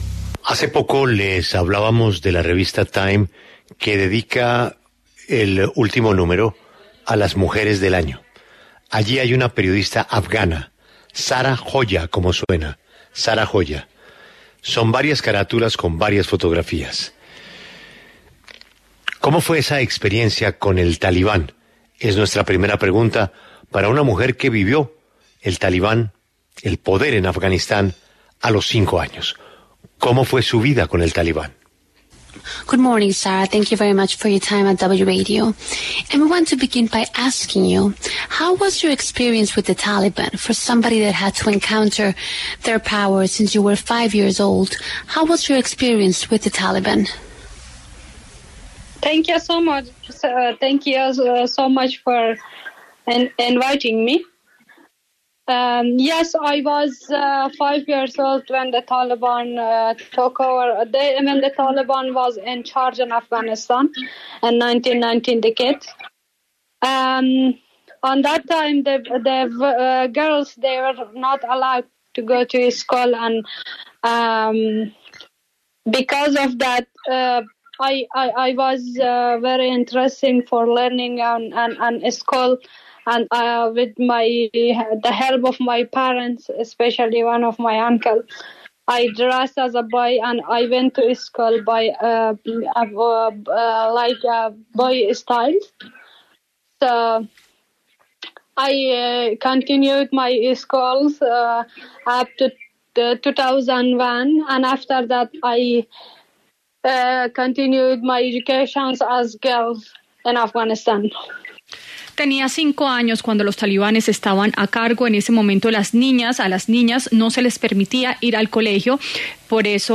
En diálogo con La W con Julio Sánchez Cristo, relató cómo vivió su niñez para acceder a la educación en medio de la toma de los talibanes.
En el encabezado escuche la entrevista completa con Zahra Joya, periodista afgana que fue portada de la revista Time.